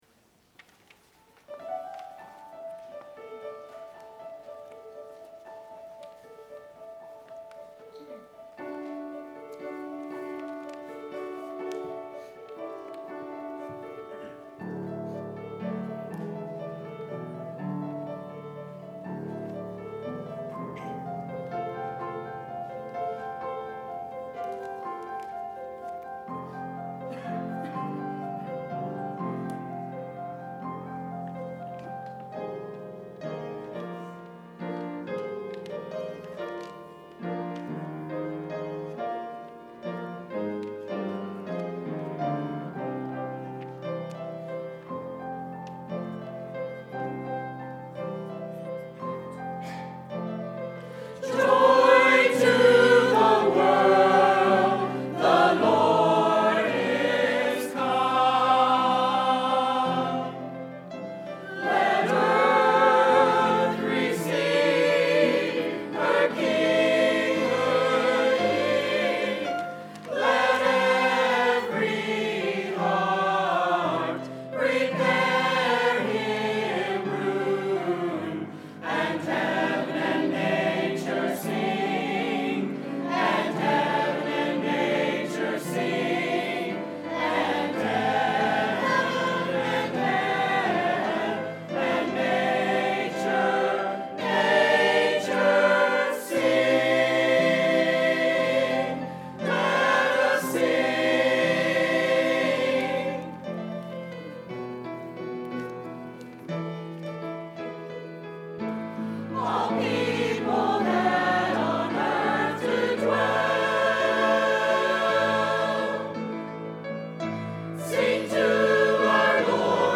Tramway Baptist Church Sermons